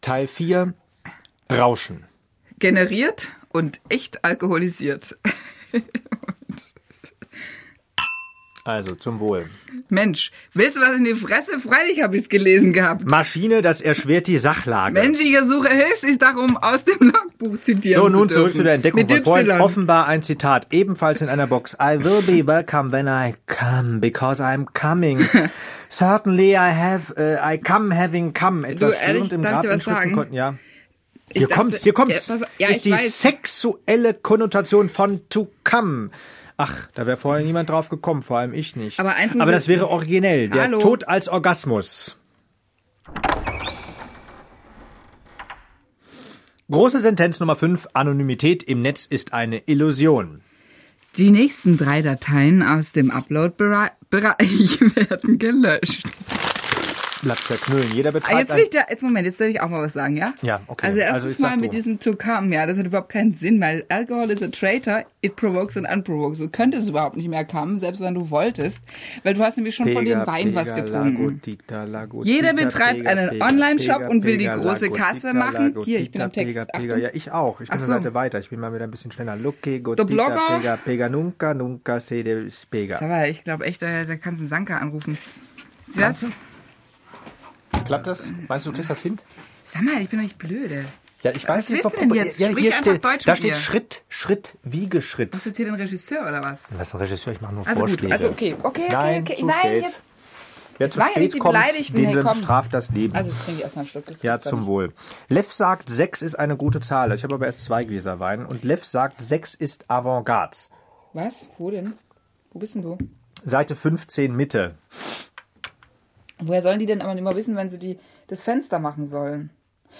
radio version gesendet vom ORF Kunstradio am 07.09.2003
Teil 4: rausch/en [generiert & alkoholisiert] - Sendung ORF Kunstradio 7.9.03 [